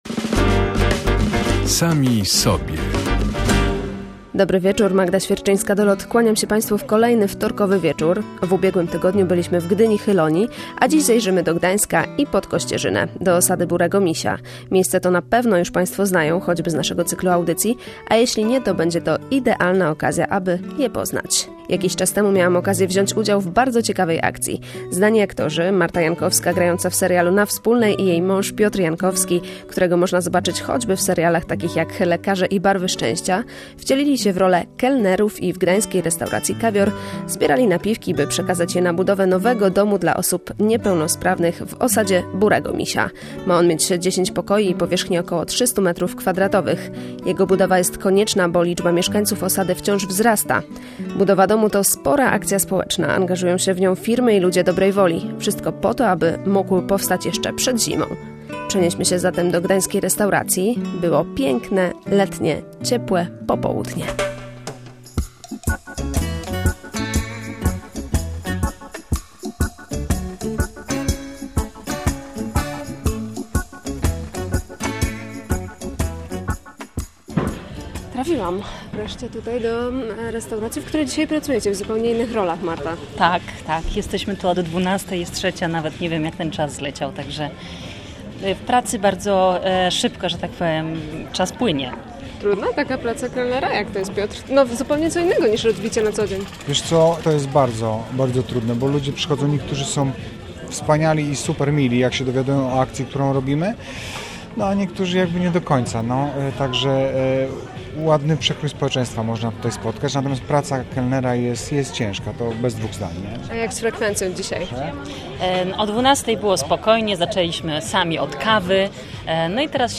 /audio/dok1/ssosada.mp3 Tagi: dokument